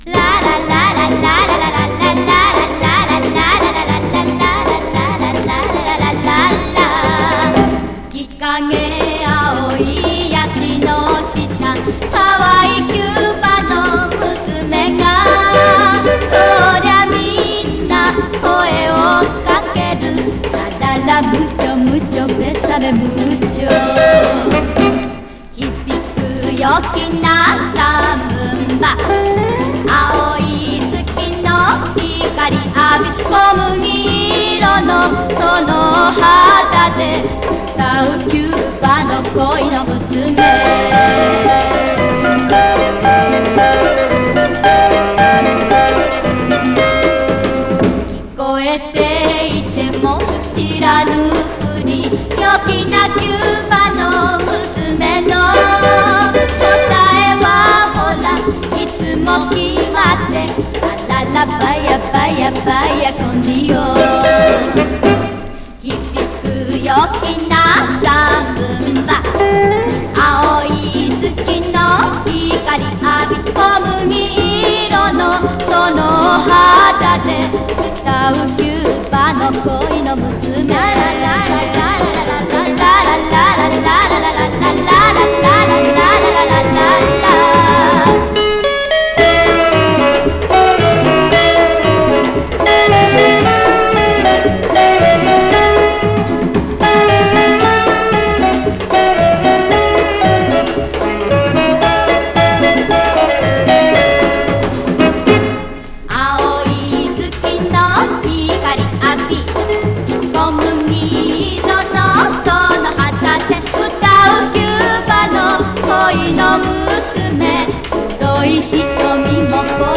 Mono